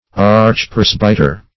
Archpresbyter \Arch`pres"by*ter\, n.